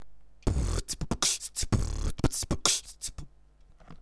Форум российского битбокс портала » Реорганизация форума - РЕСТАВРАЦИЯ » Выкладываем видео / аудио с битбоксом » Мои биты (Сюда быду выкладывать все известные мне биты)
в микрофоне звук конечно искажается, поэтому кач-во неочень